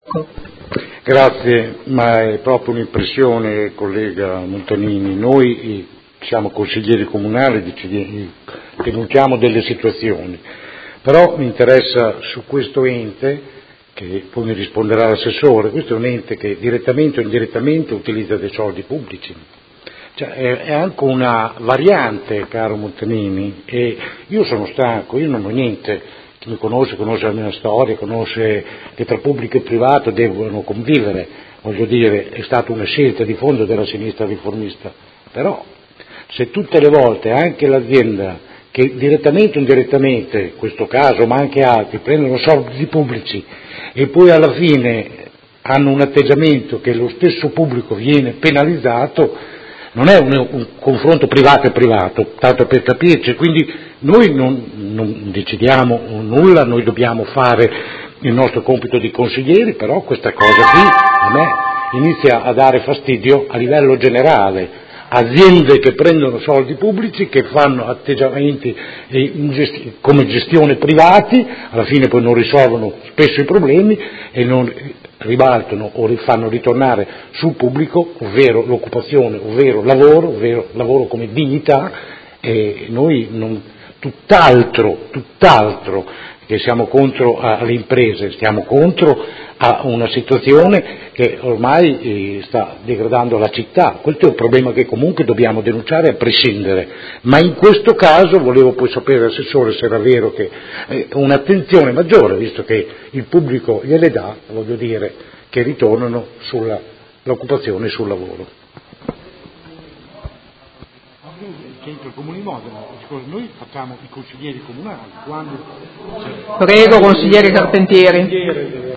Seduta del 25/05/2017 Dibattito. Interrogazioni 61274 e 62840 sui lavoratori di Hesperia Hospital.